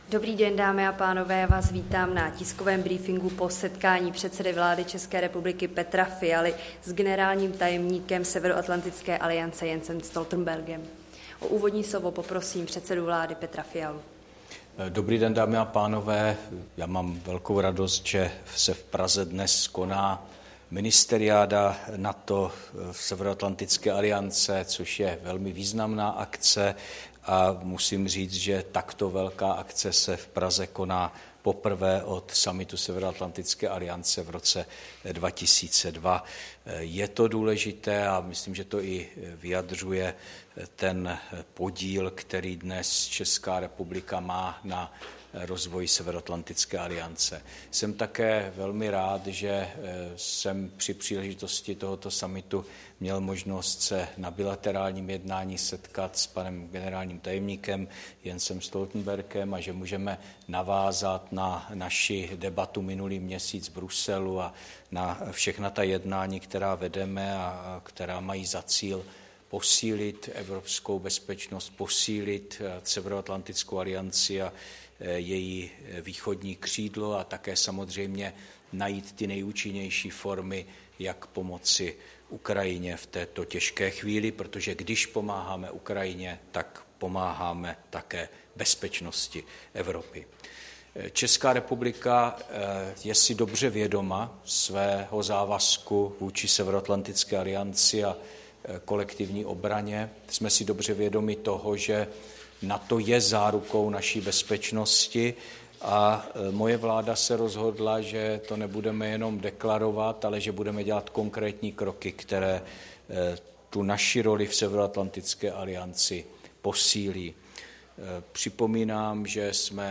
Tisková konference premiéra Fialy s generálním tajemníkem NATO Jensem Stoltenbergem (v češtině), 30. května 2024